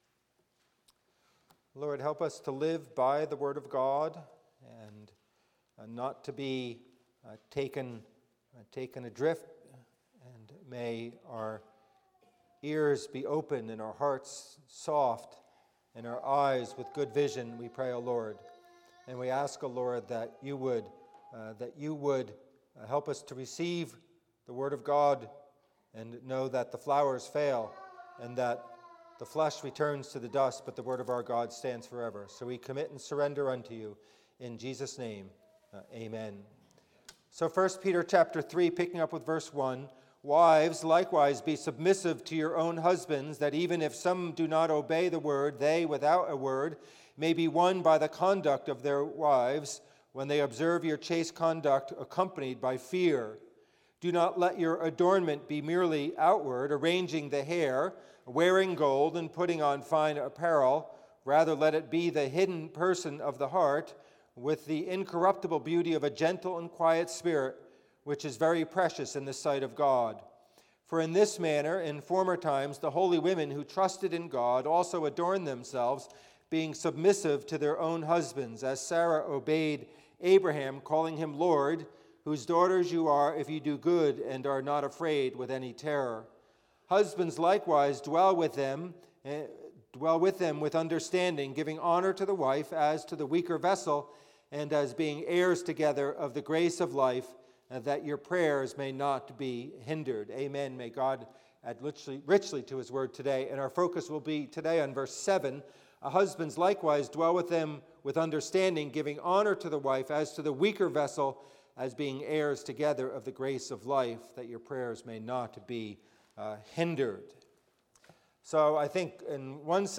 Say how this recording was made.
Passage: 1 Peter 3:1-7 Service Type: Worship Service